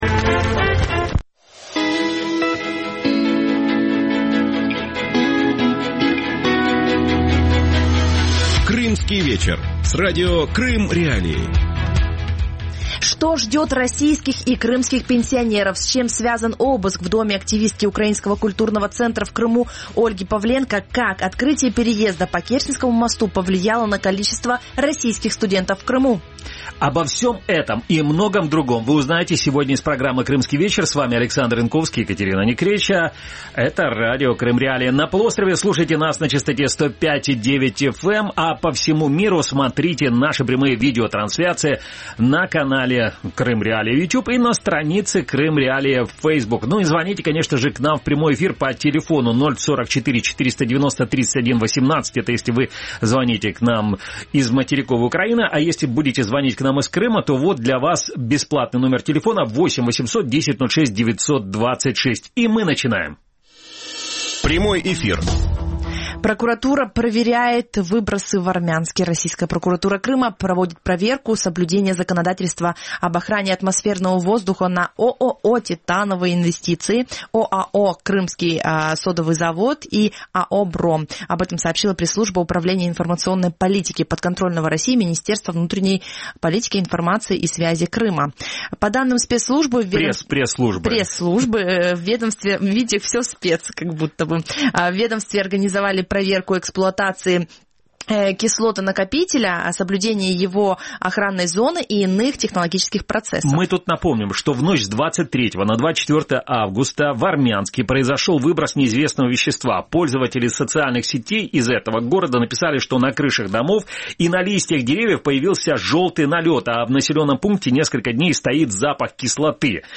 «Крымский вечер» – шоу, которое выходит в эфир на Радио Крым.Реалии в будни с 18:30 до 19:30.